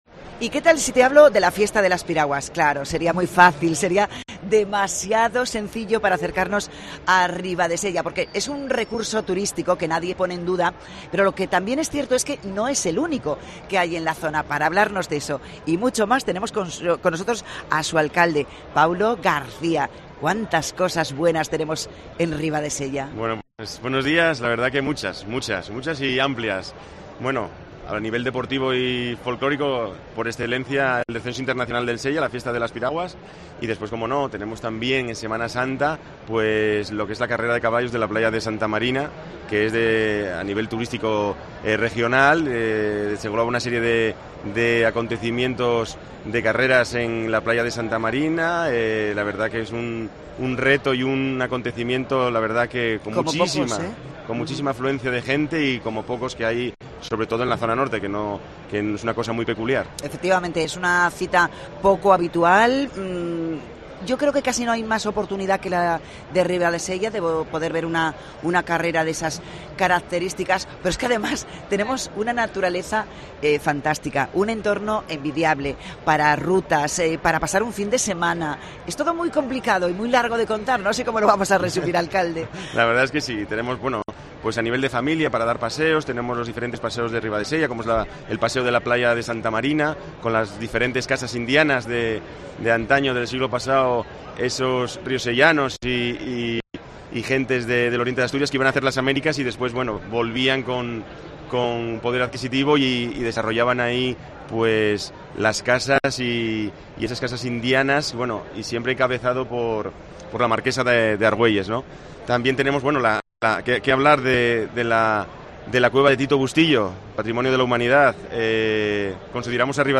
FITUR 2024: Entrevista a Paulo García, alcalde de Ribadesella